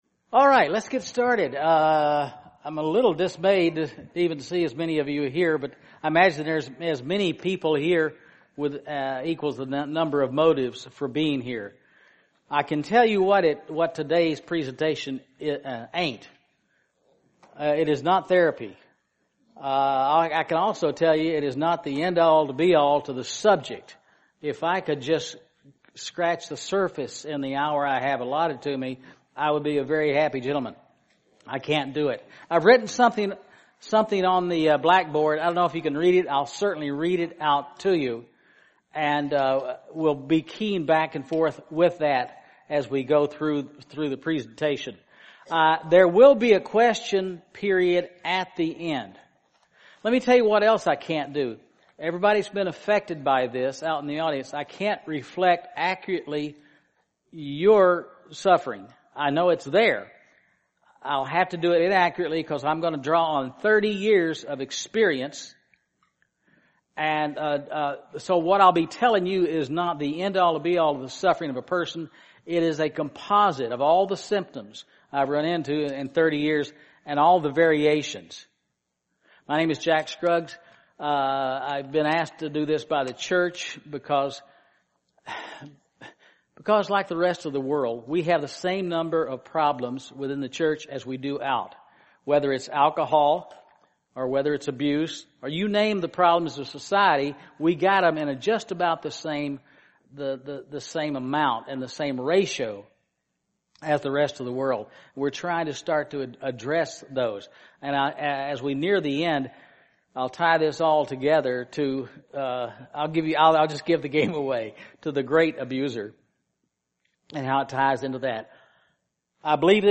This sermon was given at the Bend, Oregon 2013 Feast site.